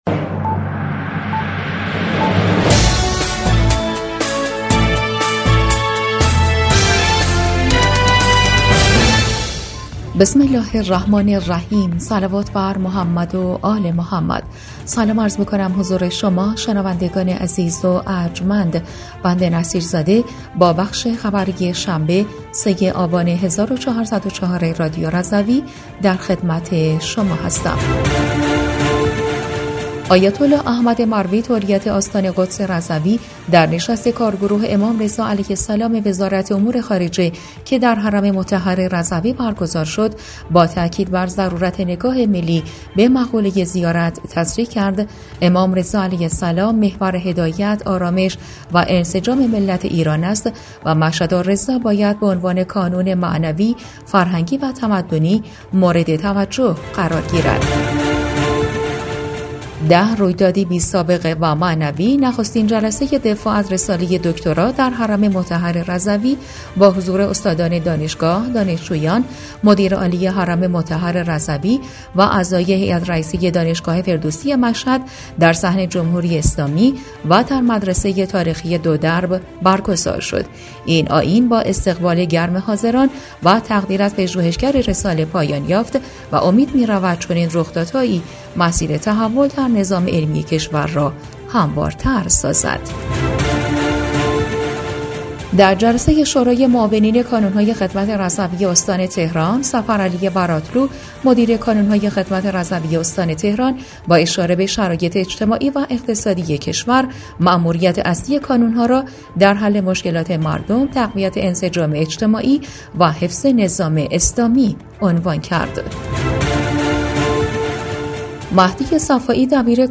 بسته خبری ۳ آبان ۱۴۰۴ رادیو رضوی؛